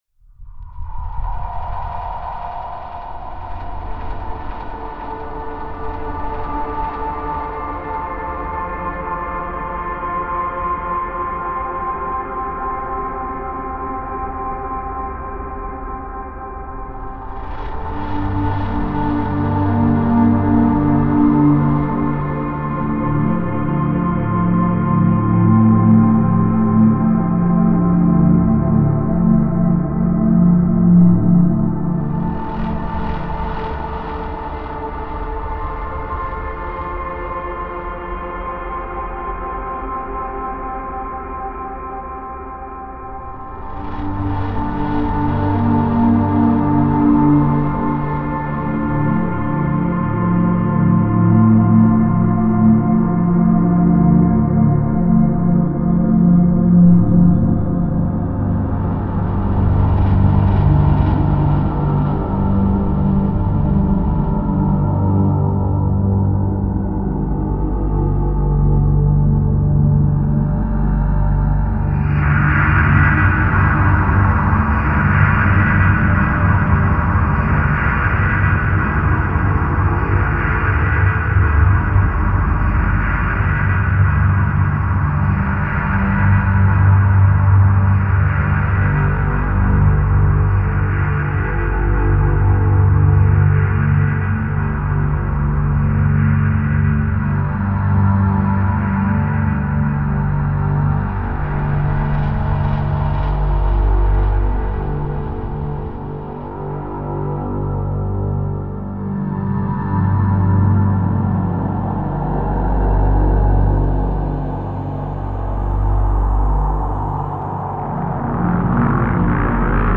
Genre: Dark Ambient.